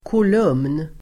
Uttal: [kol'um:n]